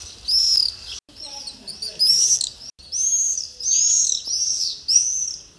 Apus apus - Swift - Rondone
DATE/TIME: 13/may/2007 (9 a.m.) - IDENTIFICATION AND BEHAVIOUR: Some swifts - well in sight - are flying over a small village, performing aerial pursuits.
The second spectrogram shows calls uttered by two or more birds together (see green box on the spectrogram). Background: human voices and Sparrow (Passer italiae) calls.